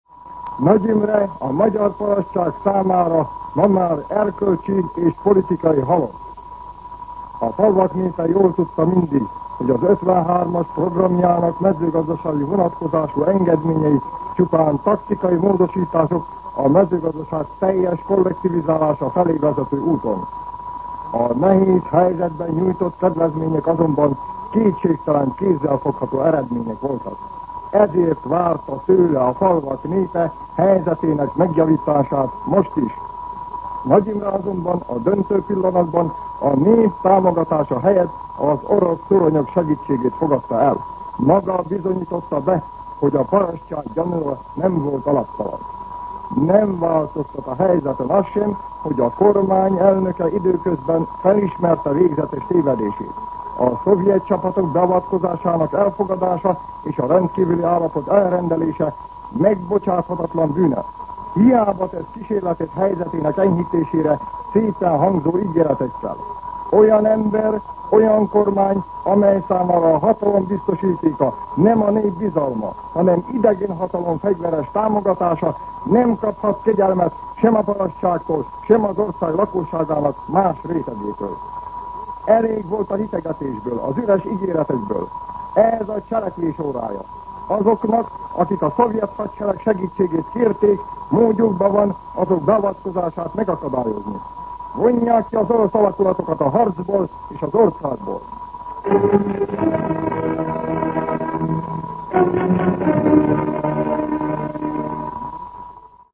Szignál
MűsorkategóriaKommentár